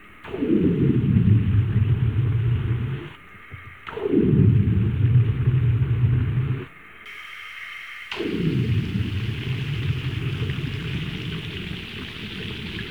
Index of /90_sSampleCDs/E-MU Producer Series Vol. 3 – Hollywood Sound Effects/Water/UnderwaterDiving
UNDERWATE01L.wav